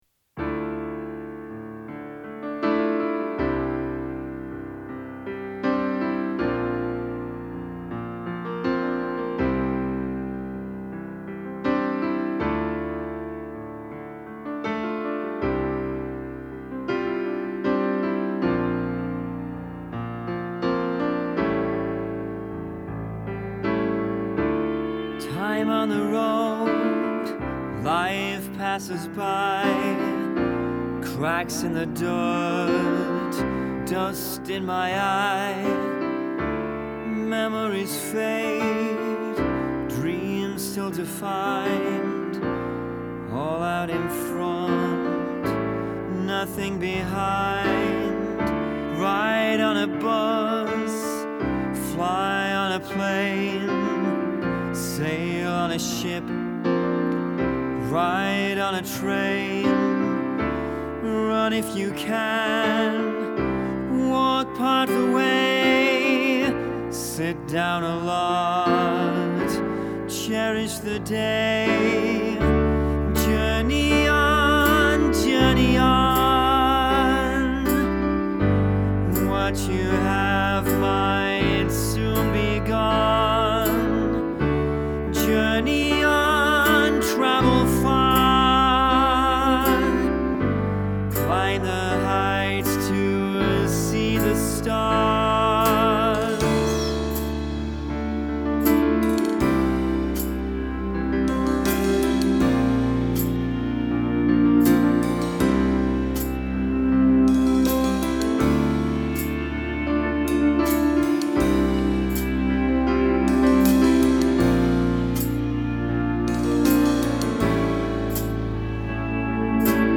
Piano/Vocals
Guitars
Bass
Drums/Percussion